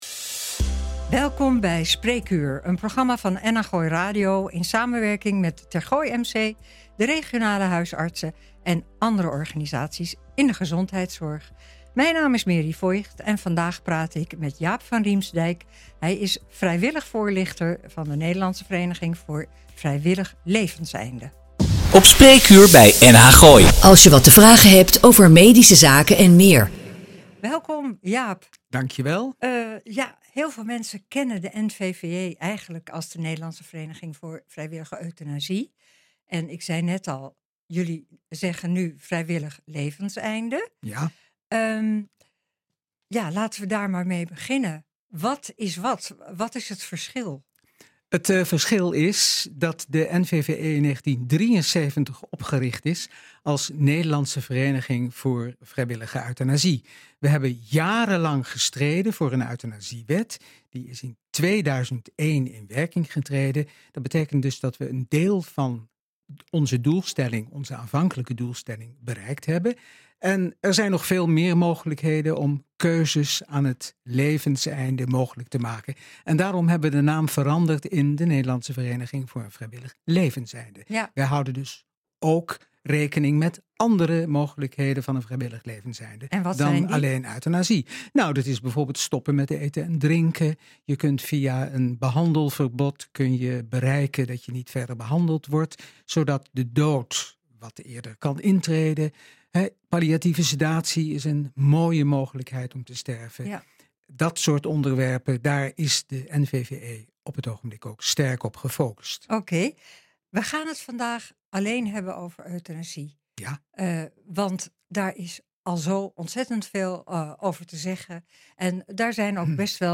Een uitzending van NH Gooi Spreekuur geheel gewijd aan Euthanasie en de rol die de NVVE kan spelen bij een euthanasie verzoek.